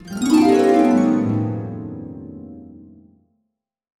Magical Harp (4).wav